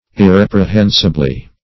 -- Ir*rep`re*hen"si*ble*ness , n. -- Ir*rep`re*hen"si*bly , adv.